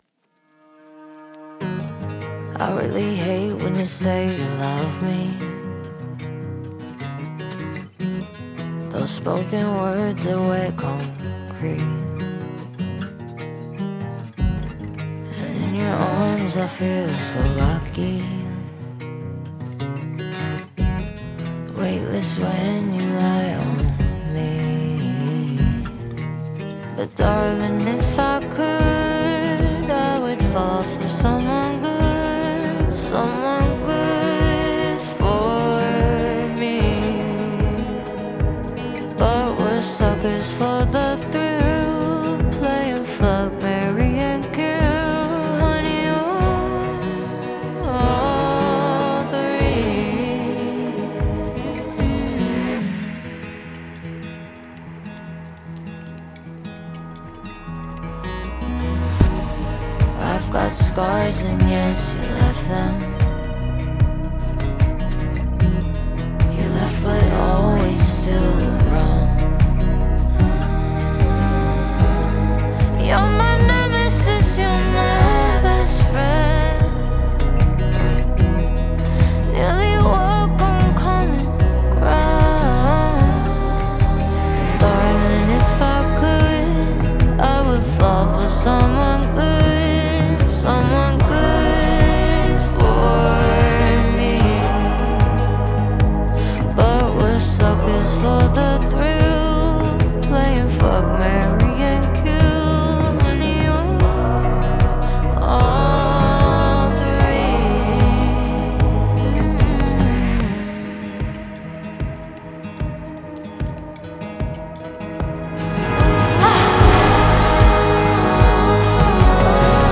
это трек в жанре поп